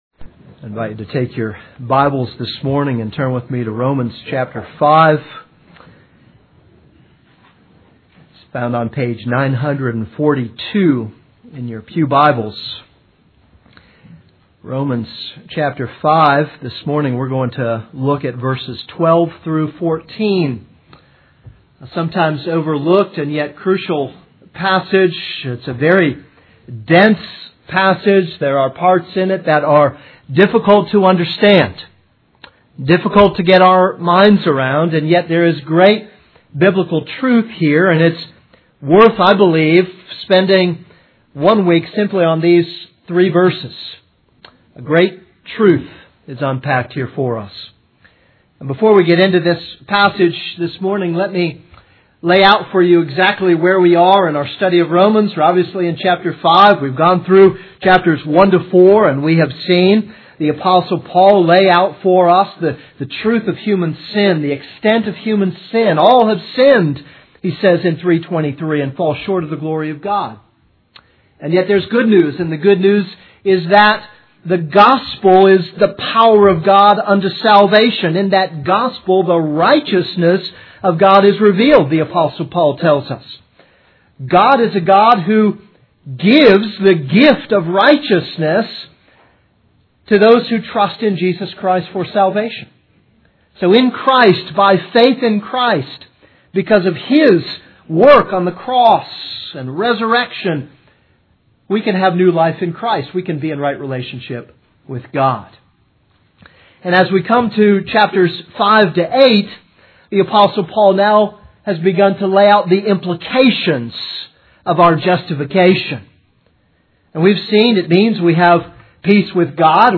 This is a sermon on Romans 5:12-14.